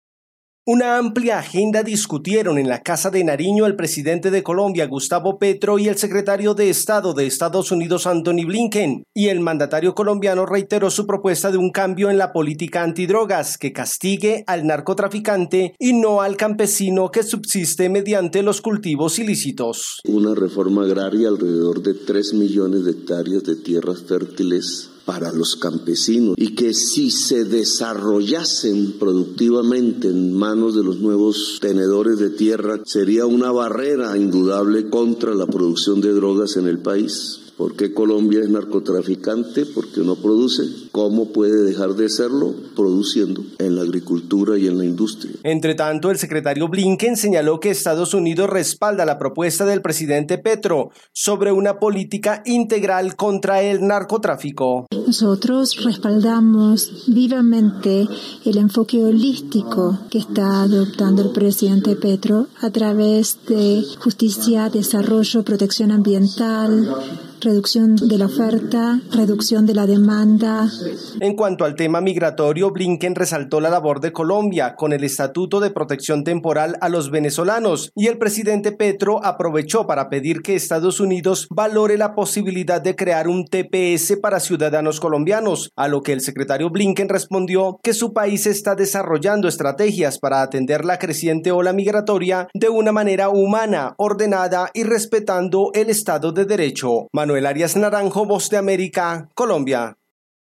La lucha contra el narcotráfico, apoyo a la implementación de los acuerdos de paz y el fenómeno migratorio, fueron los temas abordados en la reunión entre el presidente Gustavo Petro y Antony Blinken. Desde Colombia informa el corresponsal de la Voz de América